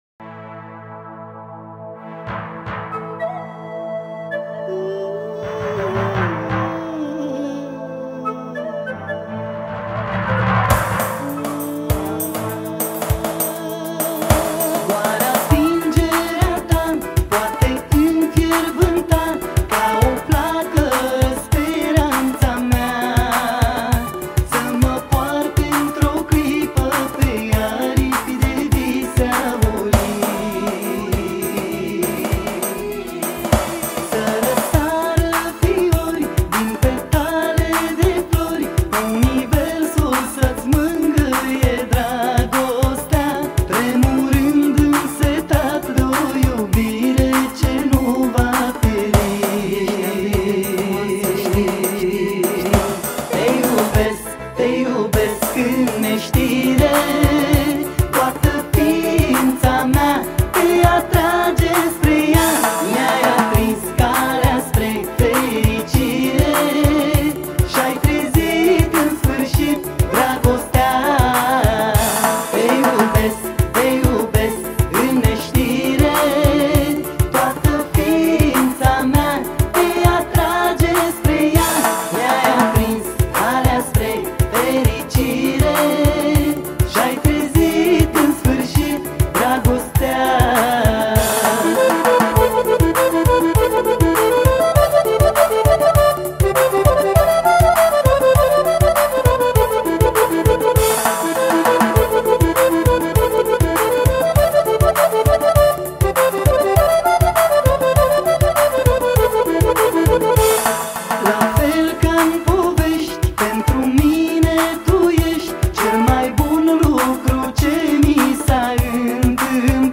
Data: 30.09.2024  Manele New-Live Hits: 0